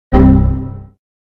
Convert some stereo audio into mono
windowsXP_error.ogg